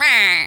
duck_quack_hurt_04.wav